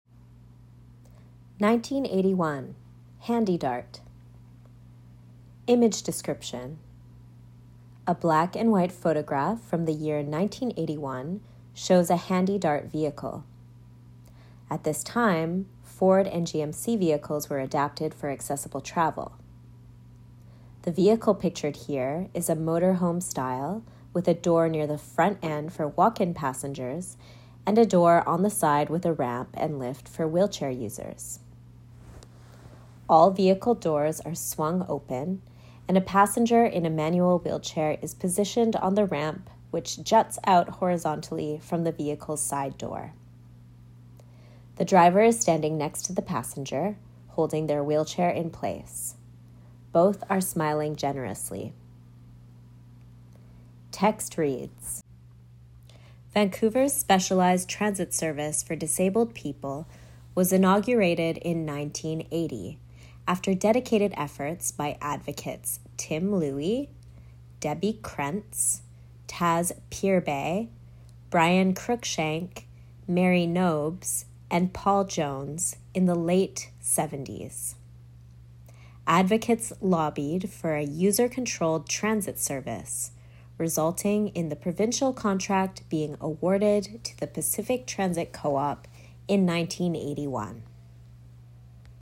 1981 HandyDart Protest – Audio Description Audio description of the 1981 HandyDart protest in Vancouver, part of the "Taking Care" exhibition exploring the history of disability activism in British Columbia. 1:32 1981-HandyDart